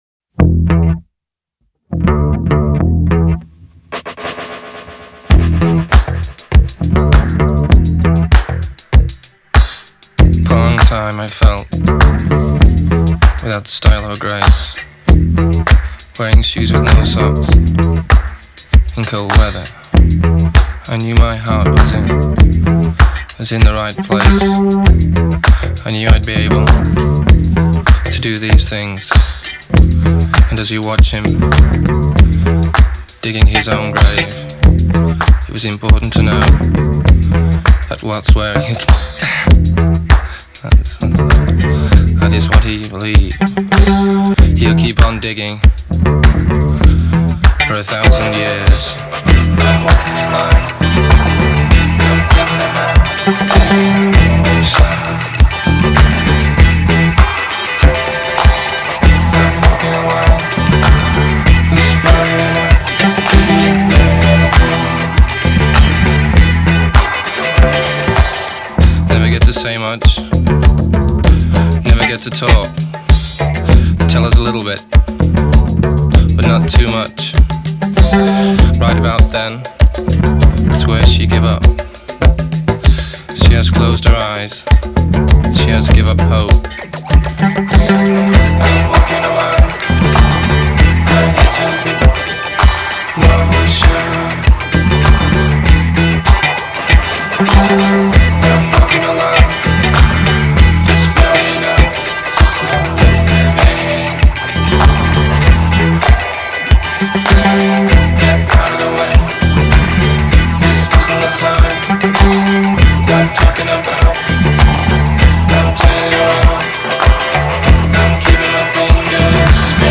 It’s the bassline that hits you first.